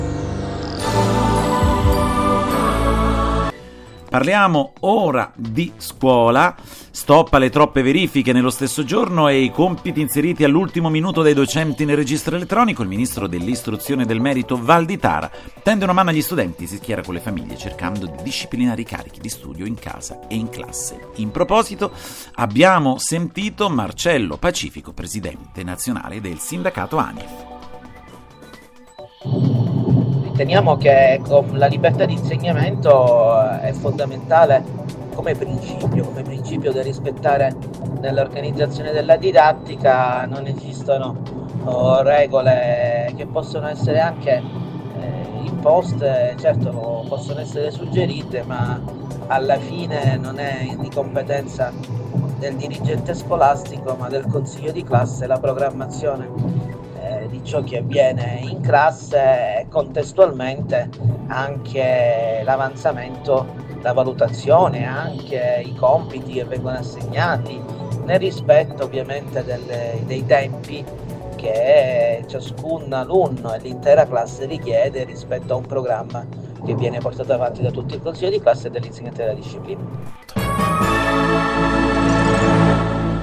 dichiarazione ai microfoni dell’emittente radiofonica Italia stampa: “riteniamo che la libertà d’insegnamento sia fondamentale come principio da rispettare nell’organizzazione della didattica, non esistono regole che possano essere imposte, possono essere suggerite.